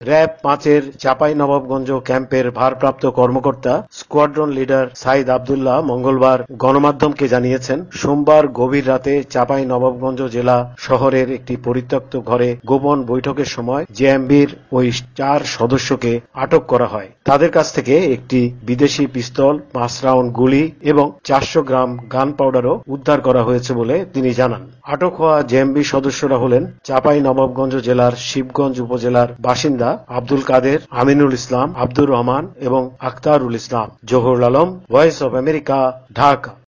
প্রতিবেদন।